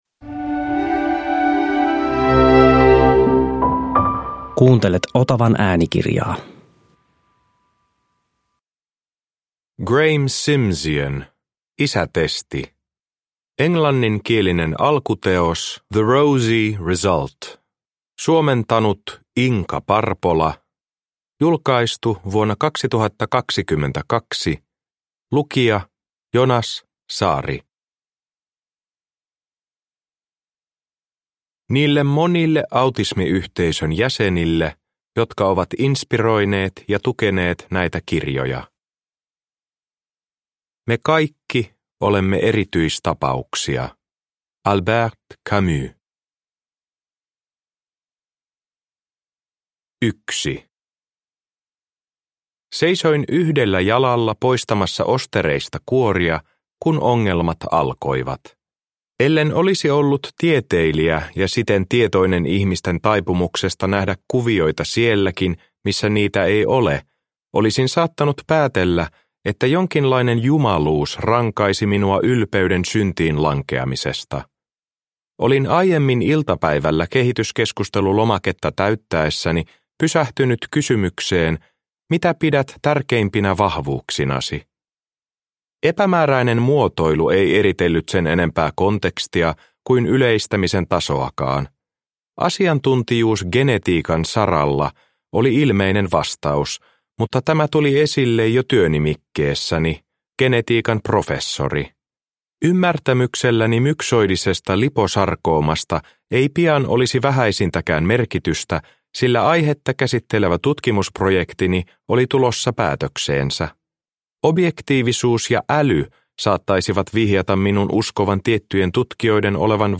Isätesti – Ljudbok – Laddas ner